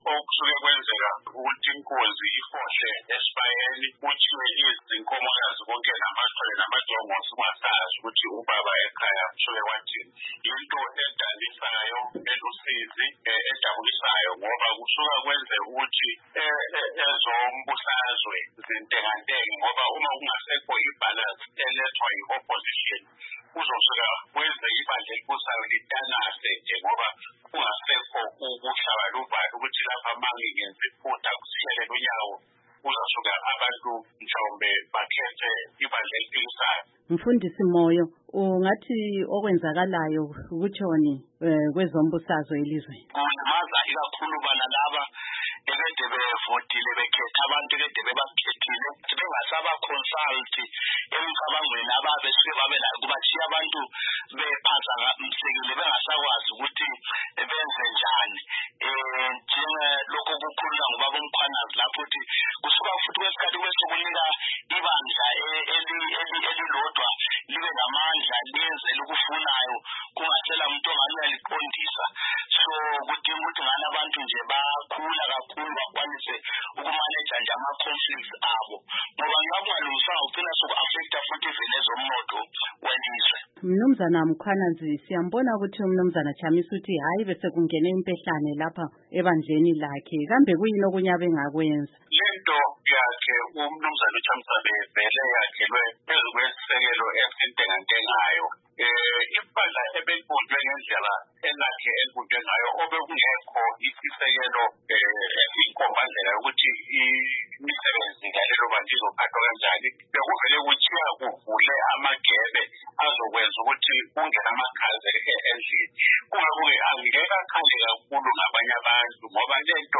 Kule ingxoxo-mpikiswano yethu sicubungula okwenzakala kubandla le CCC kulandela ukulaxazwa kwalo ngowayelikhokhela uMnu. Nelson Chamisa, lokuthi ukukhululwa kwelunga lalelibandla uMnu. Job Sikhala kungaletha mthelela bani kulelibandla lakwezombusazwe elizweni.